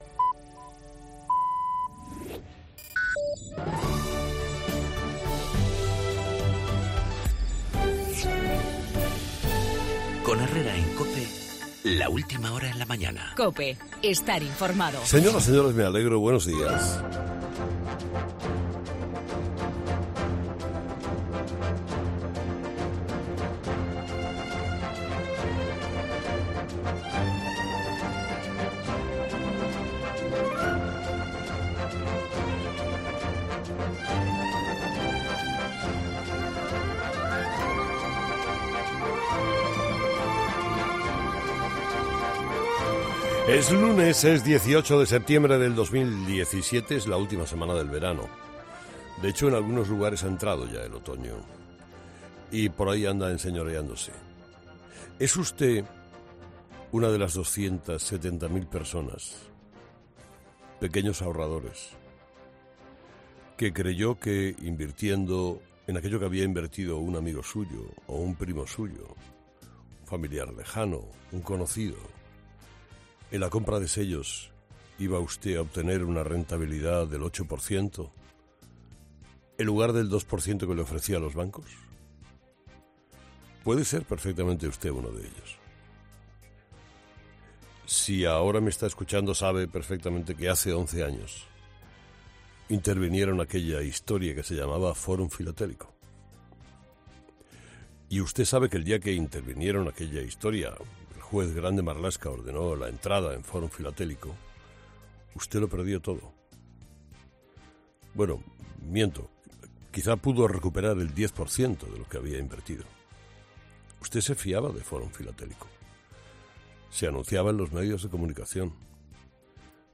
Monólogo de las 8 de Herrera
El juicio por la estafa de Fórum Filatélico que comienza once años después, en el editorial de Carlos Herrera